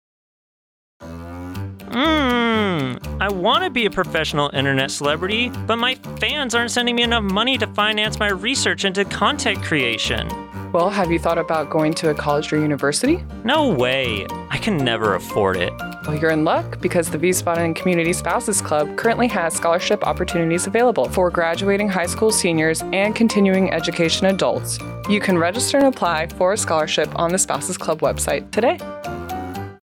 The Wiesbaden Community Spouses Club offers scholarships to graduating high schoolers and continuing education adults on Hainerberg, Germany, until March 6, 2026. This spot was created in the AFN Wiesbaden studio on Clay Kaserne Feb. 6.